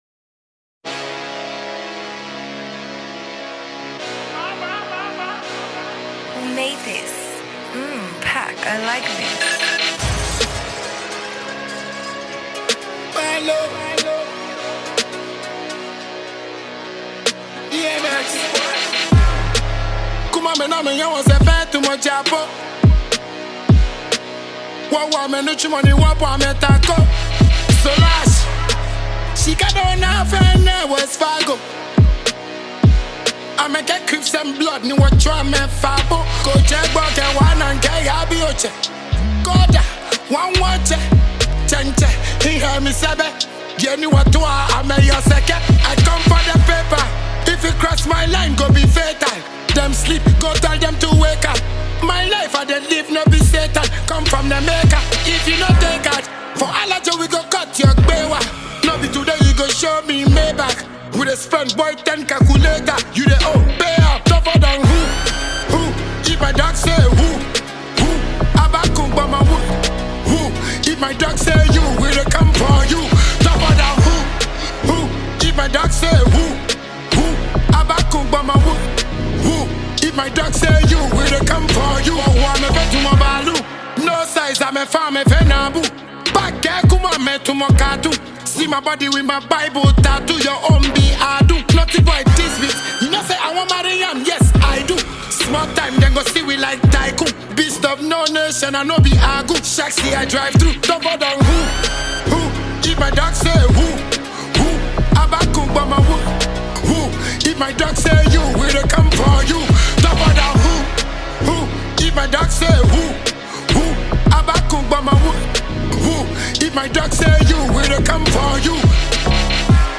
a Ghanaian dancehall act